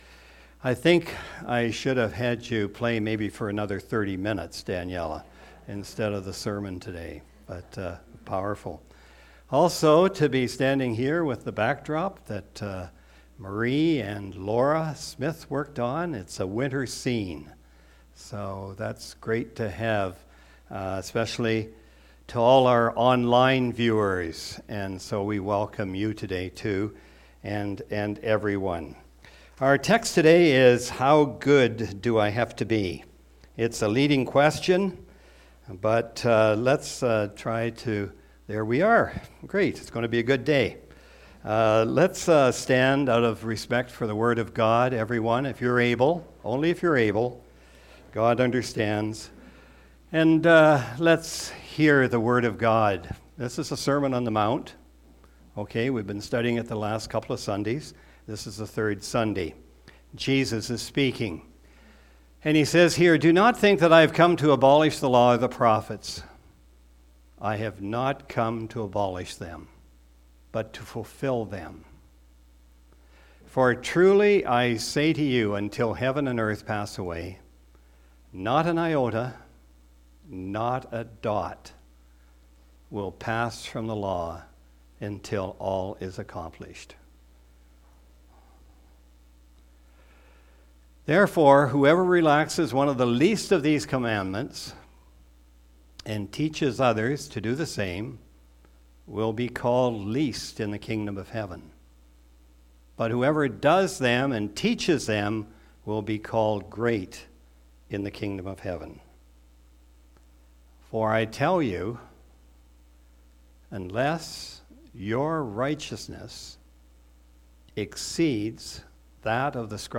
Sermon Audio and Video How good do I have to be?